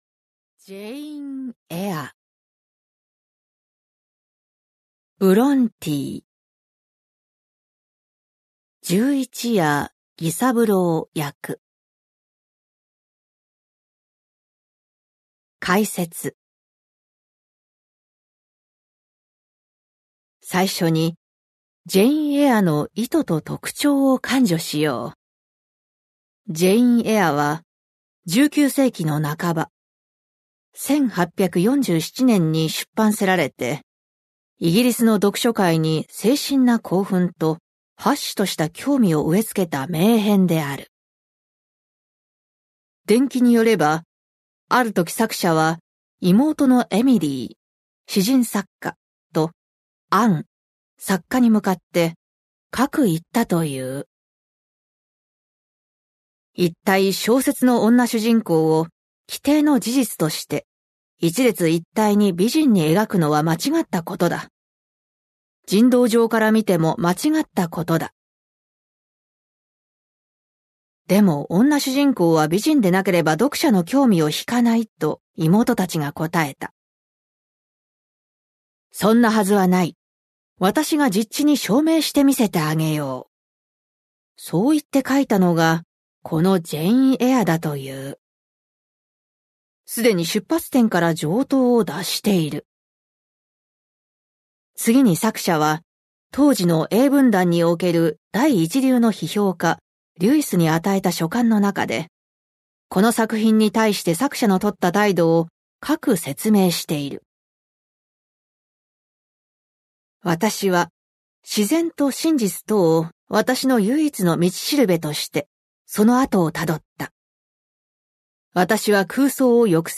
[オーディオブック] ジェーン・エア